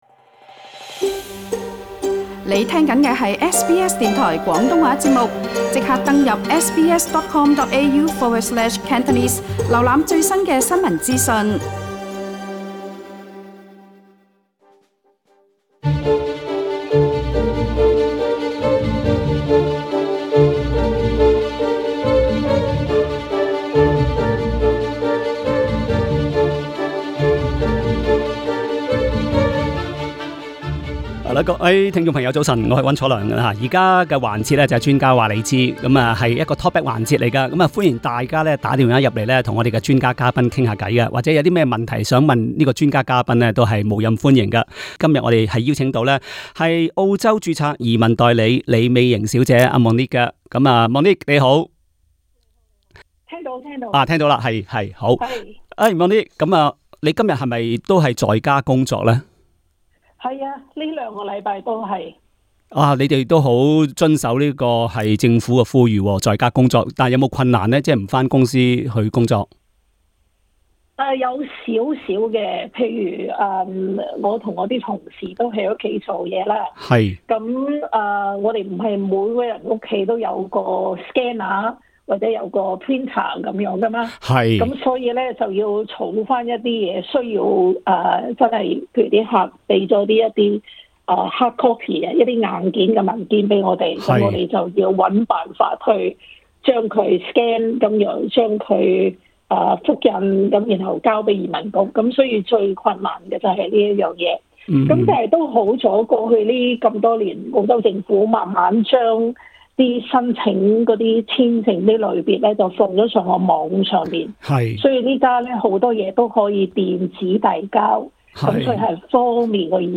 她也会回答了听衆致电提问。